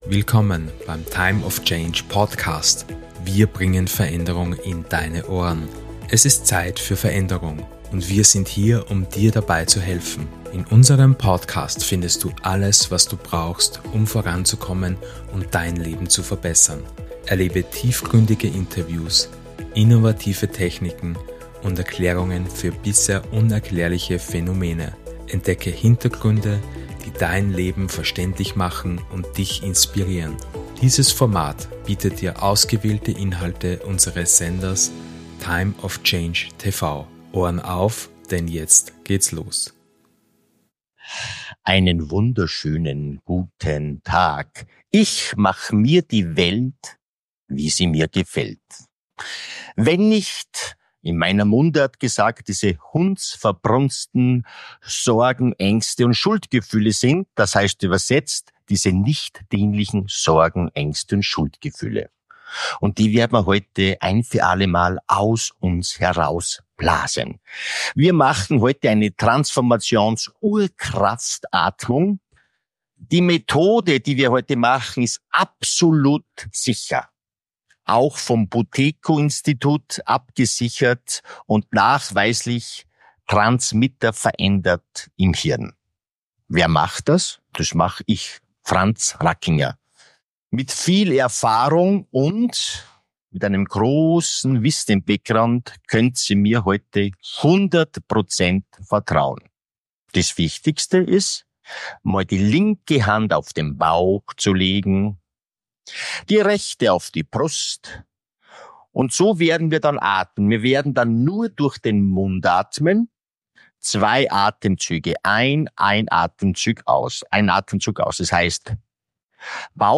Während der Session wirst Du angeleitet, Deine Aufmerksamkeit
Musik: